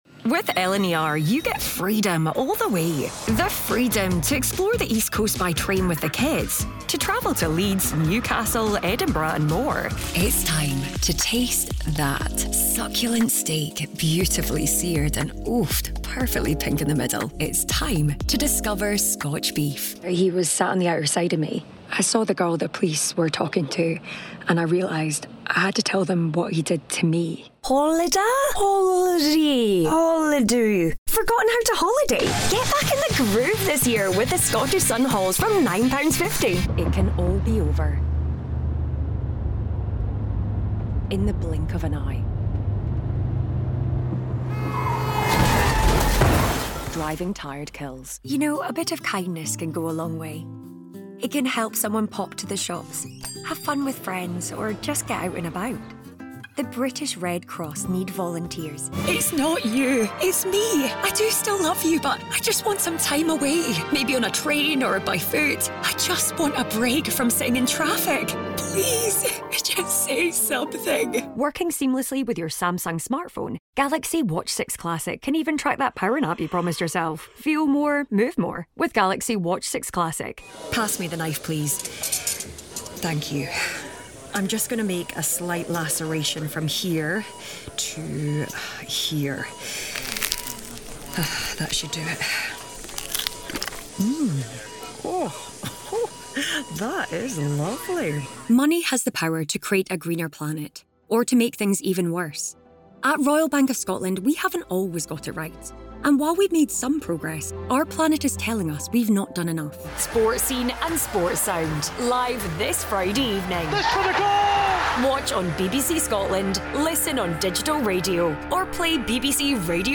Anglais (écossais)
Je suis un artiste voix off écossais à temps plein avec un studio de qualité de diffusion.
Rauque
Chaleureux
Amical